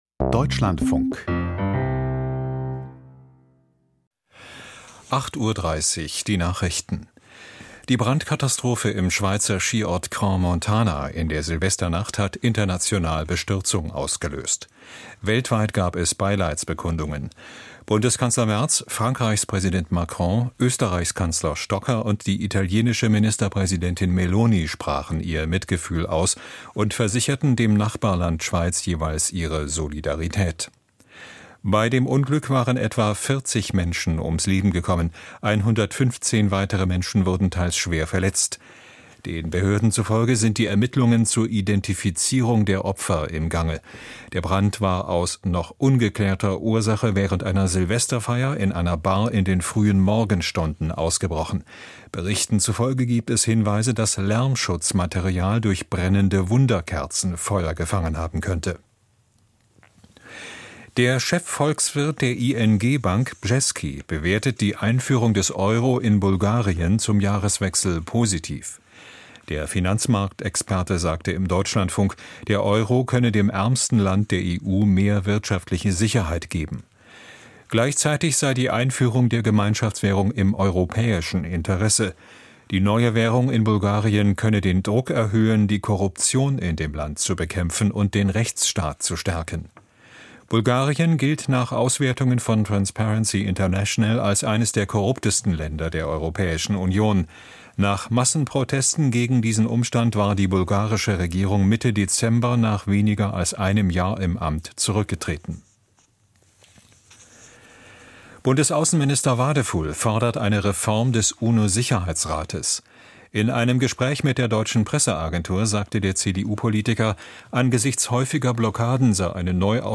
Die Nachrichten vom 02.01.2026, 08:30 Uhr
Aus der Deutschlandfunk-Nachrichtenredaktion.